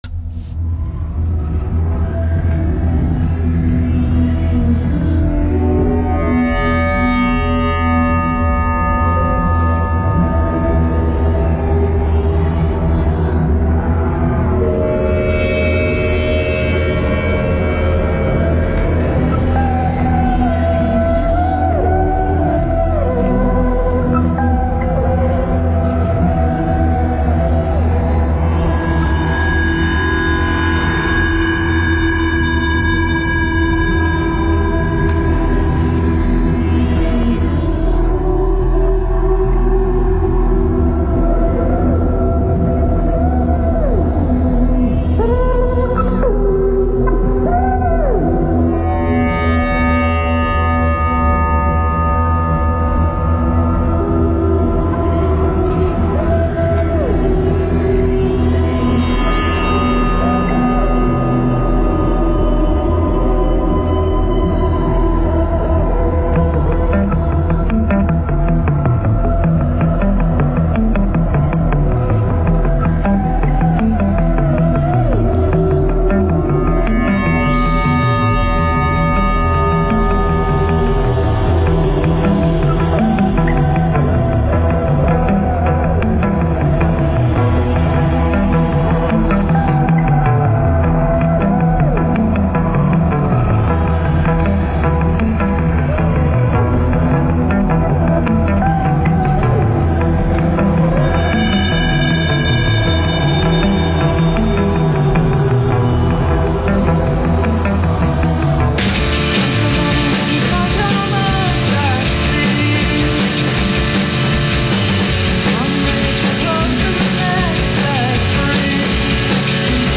recorded in session